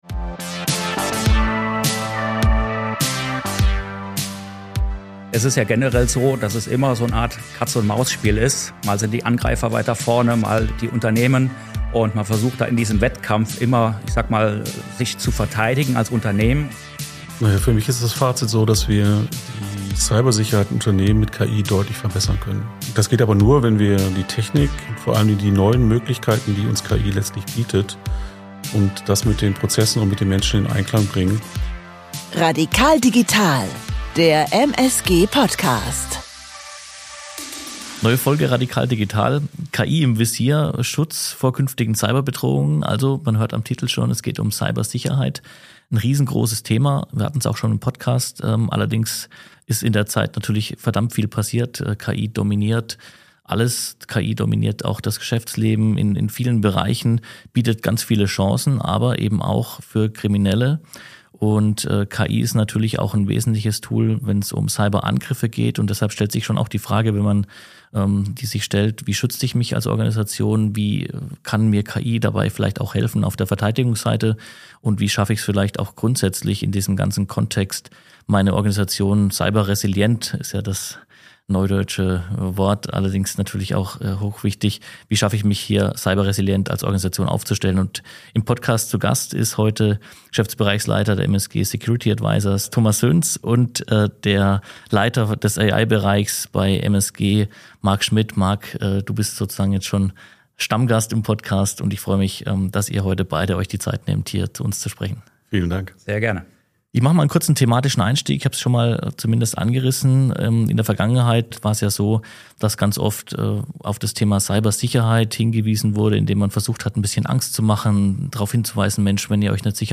zu Gast im Studio